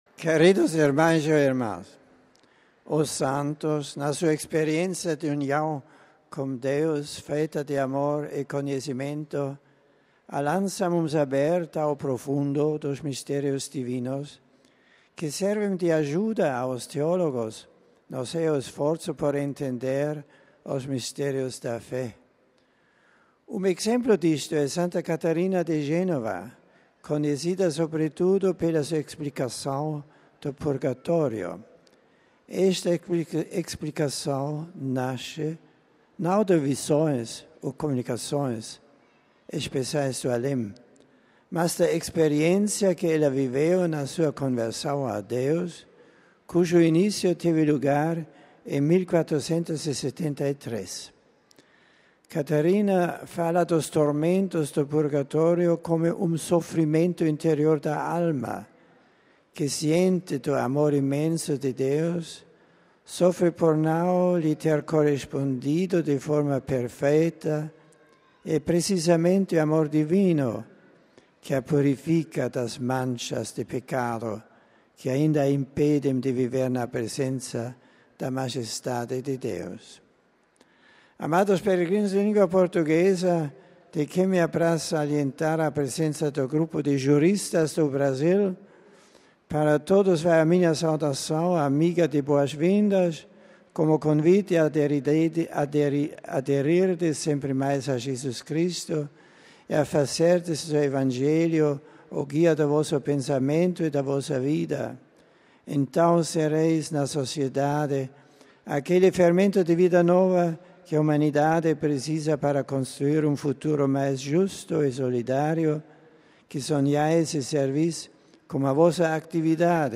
Na audiência geral Bento XVI fala de Santa Catarina de Génova e volta a salientar importância das mulheres para a sociedade e para a Igreja
Escutemos agora Bento XVI falando em português: RealAudio